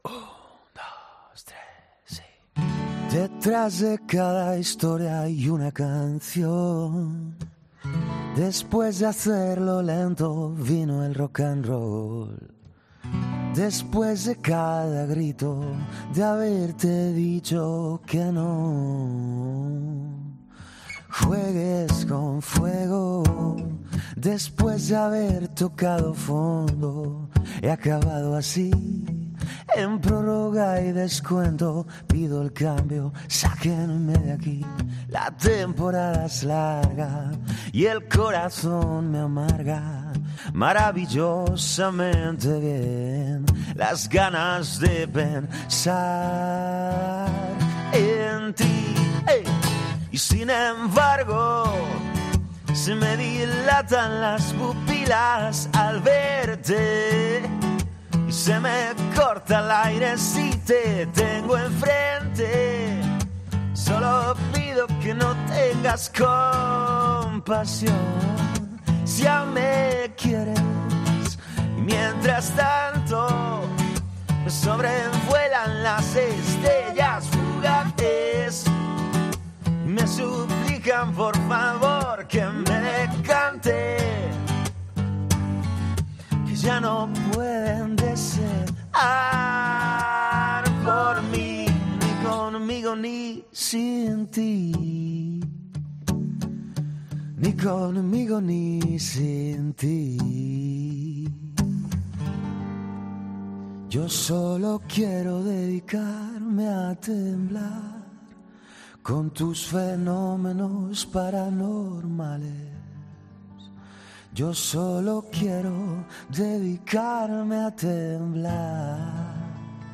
Este compositor gallego ha presentado su primer álbum después de un EP, Días grandes , cuya gira ha durado "casi dos años".